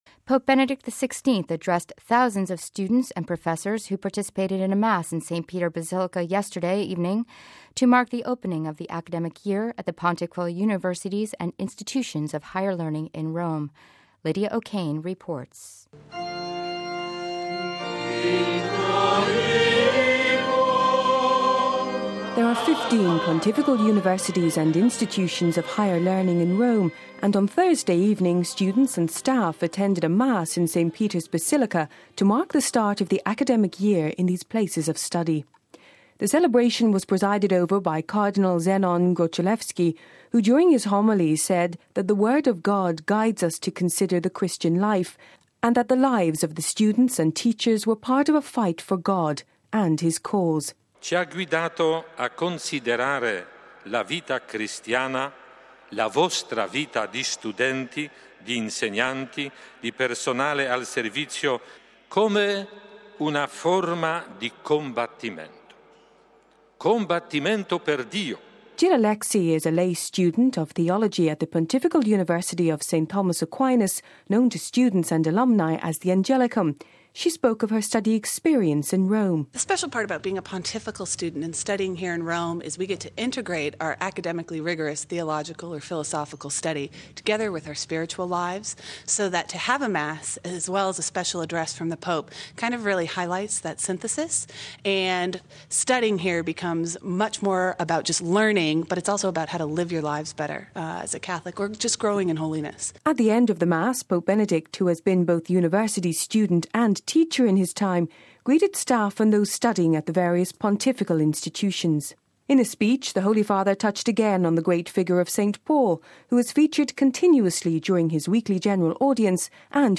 (31 Oct 08 - RV) Pope Benedict XVI addressed thousands of students and professors who participated in a Mass in St. Peter Basilica yesterday evening to mark the opening of the Academic Year at the Pontifical Universities and Institutions of Higher Learning in Rome.